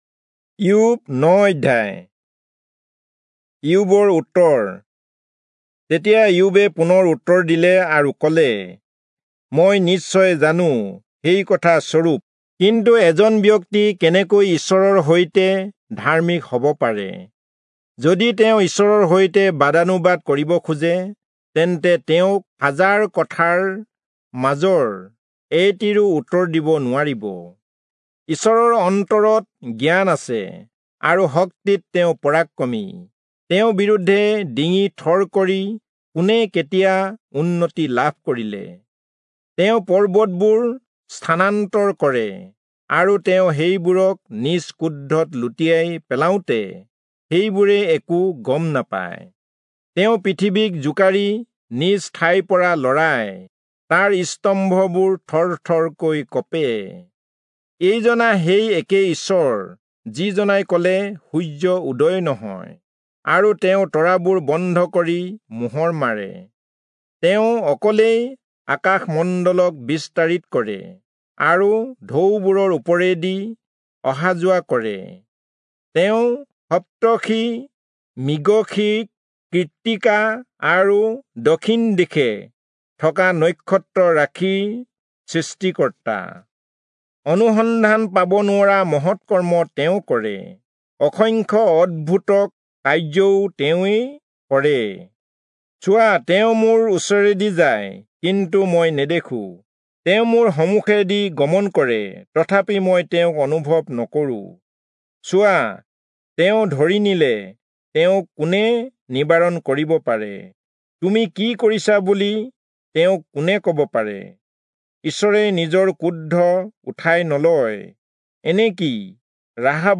Assamese Audio Bible - Job 34 in Mov bible version